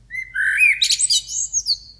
wildlife_blackbird.ogg